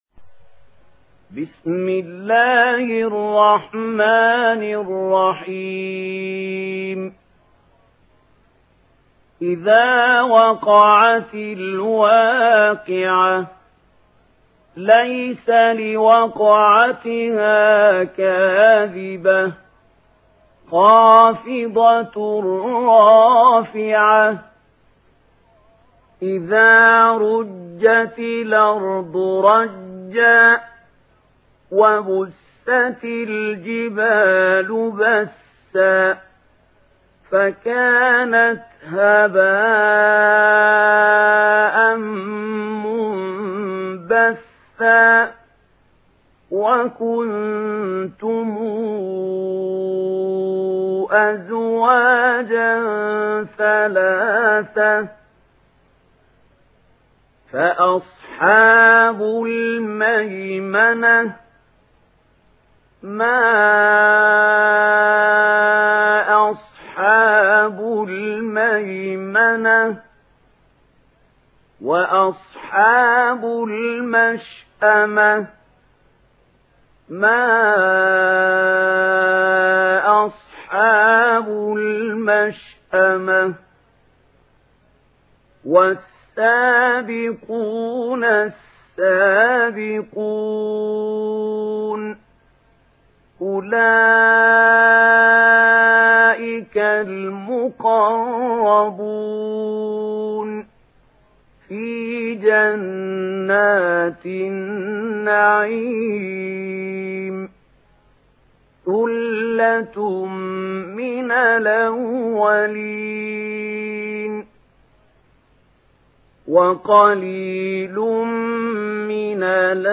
Vakia Suresi İndir mp3 Mahmoud Khalil Al Hussary Riwayat Warsh an Nafi, Kurani indirin ve mp3 tam doğrudan bağlantılar dinle